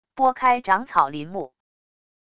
拨开长草林木wav下载